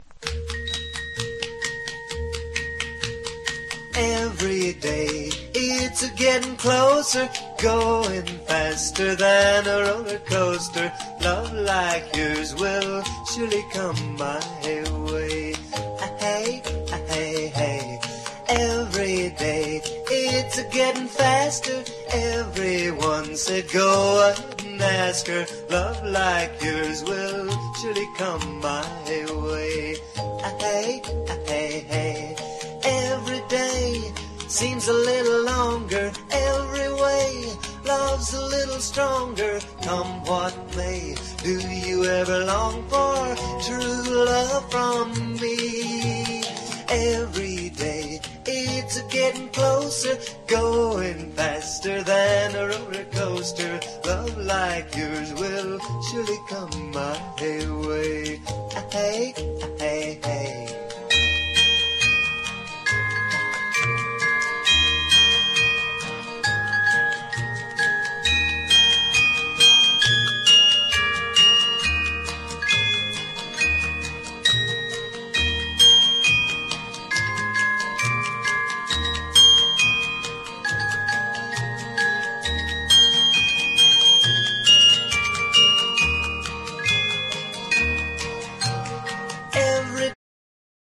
OLDIES / BLUES